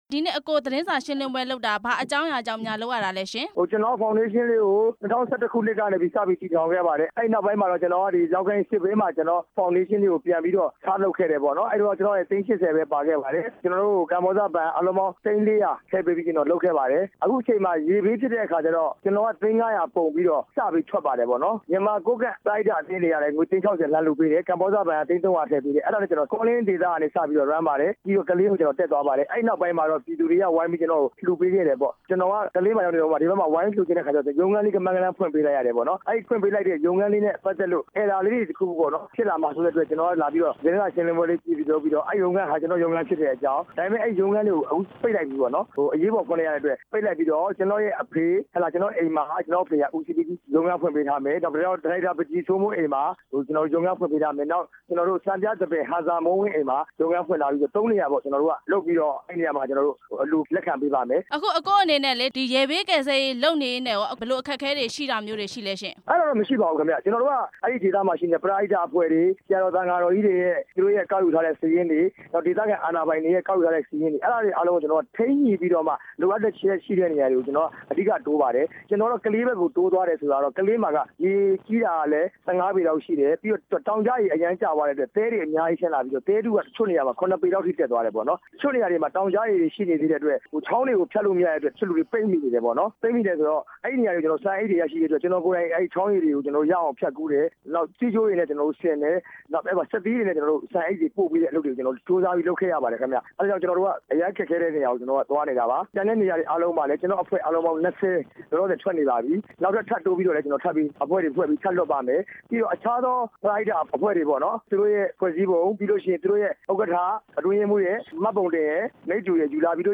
ဝေဠုကျော်ဖောင်ဒေးရှင်းက သူတို့တွေ့ကြုံရတဲ့ အခြေအနေနဲ့ ဖေါင်ဒေးရှင်းရဲ့ လုပ်ငန်းတွေကို ရှင်းပြတဲ့ သတင်းစာရှင်း လင်းပွဲမှာ ရုပ်ရှင်သရုပ်ဆောင် ကိုဝေဠုကျော် က ပြောခဲ့တာဖြစ်ပါတယ်။